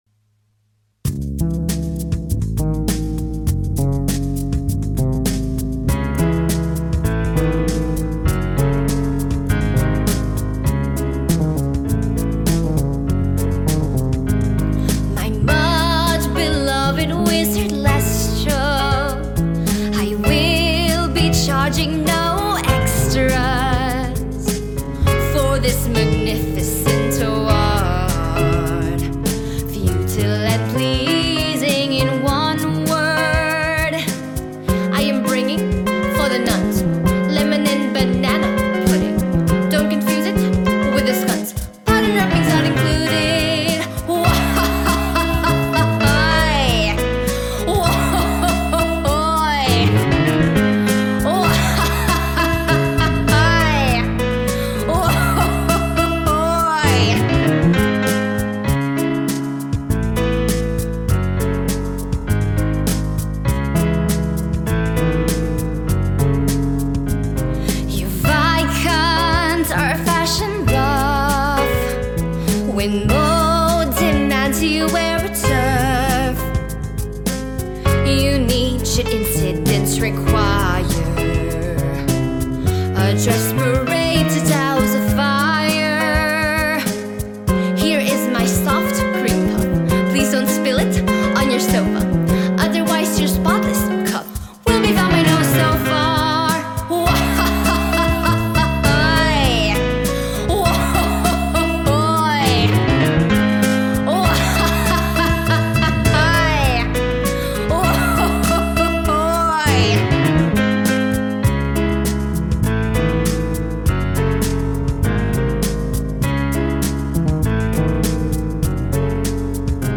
Our characters sing and keep you connected to the theme of the story.